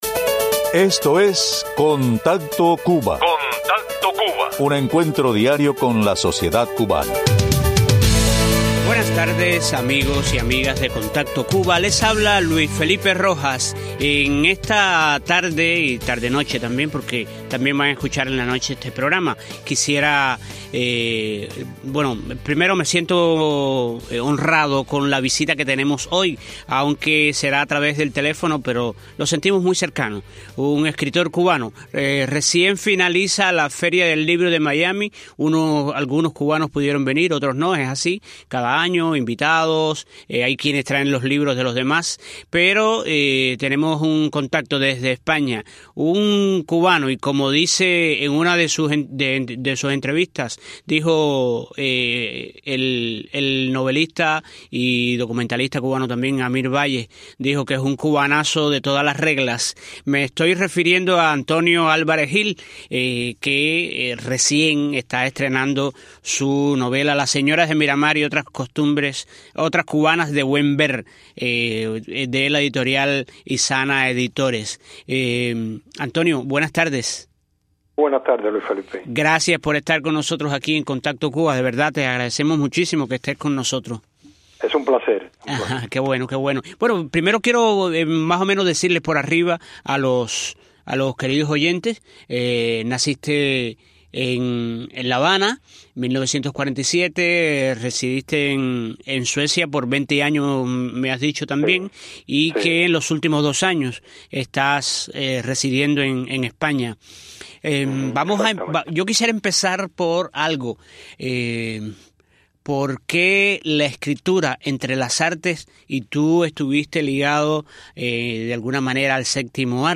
Conversación con el novelista cubano